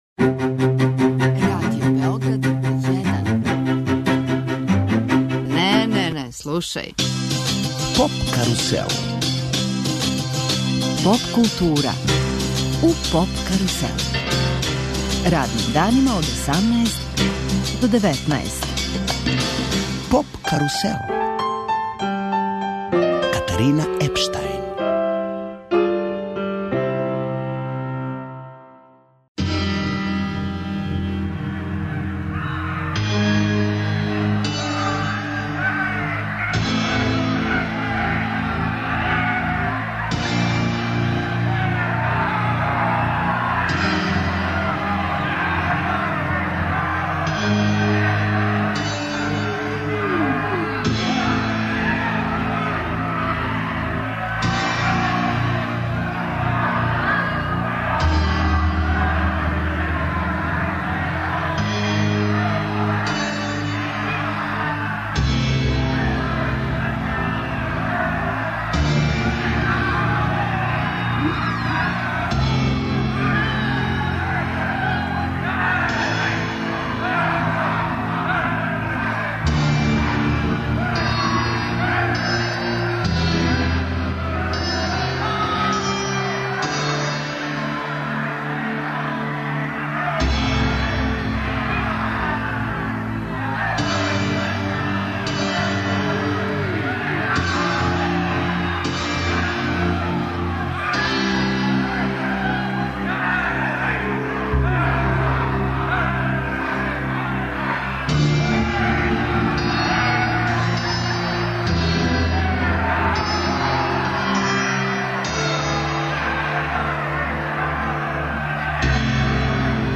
Гости емисије су чланови Хоркестра, поводом концерта који ће овај хор и оркестар да одржи 6. фебруара у сали Американа, Дома омладине.